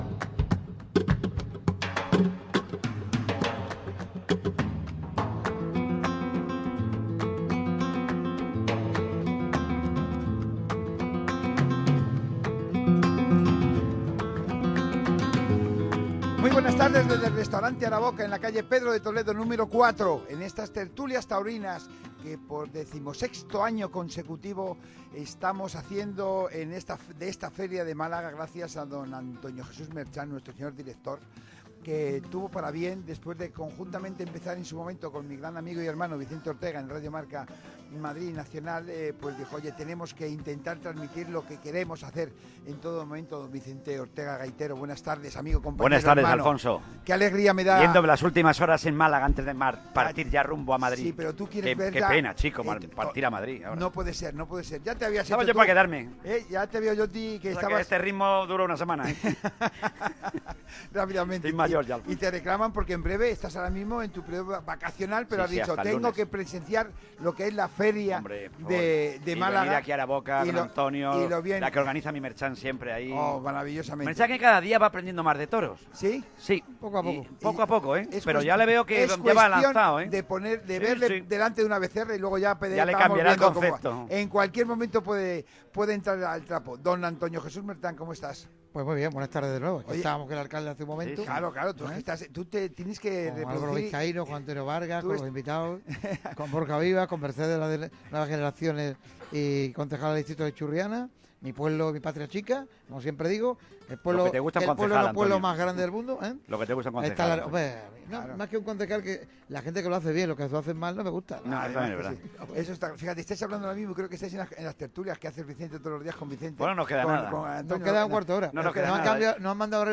Debate de lujo en otro Marca Toros de grana y oro en Araboka - Radio Marca Málaga